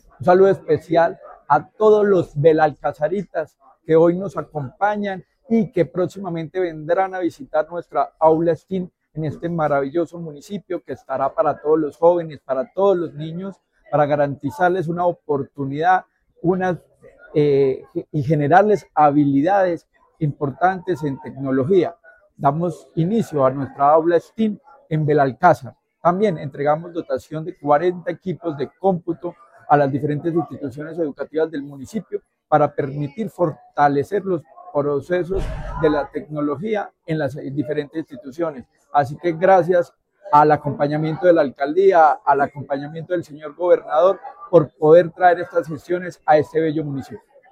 Secretario de Educación de Caldas, Luis Herney Vargas Barrera.
Secretario-de-Educacion-Luis-Herney-Vargas-Barrera-entrega-computadores-Belalcazar.mp3